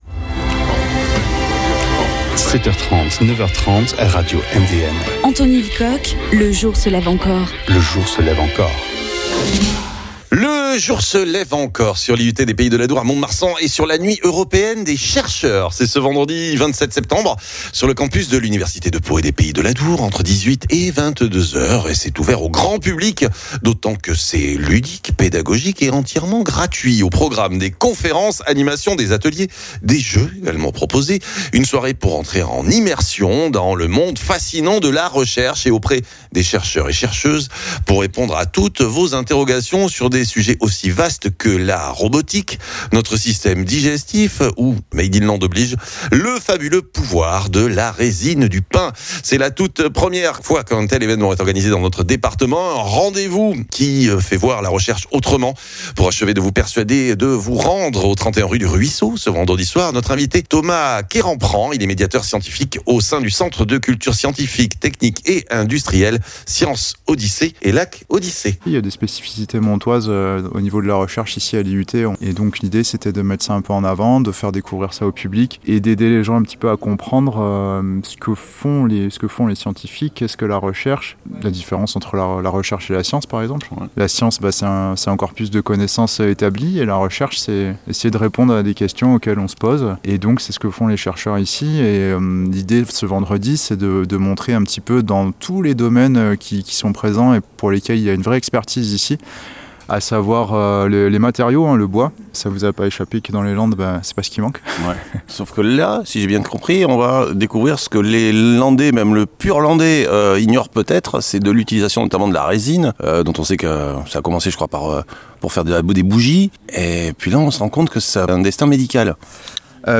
Présentation par